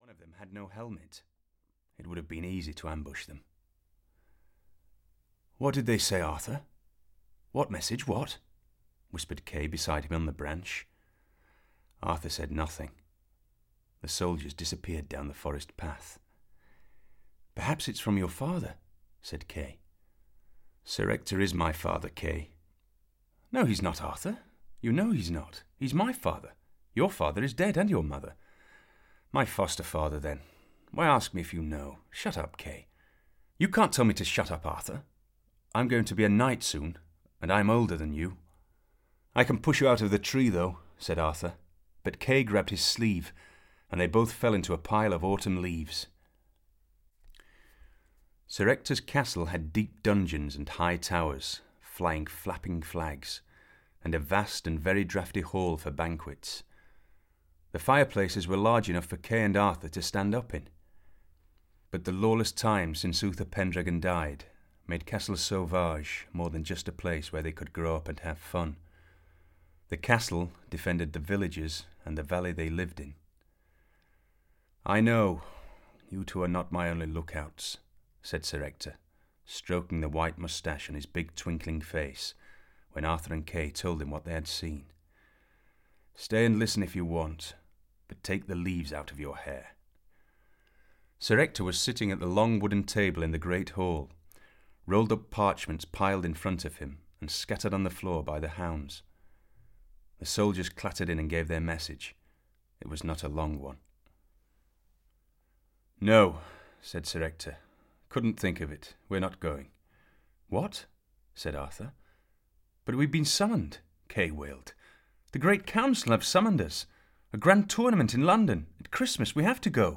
King Arthur & The Knights of the Round Table (EN) audiokniha
Ukázka z knihy
• InterpretSean Bean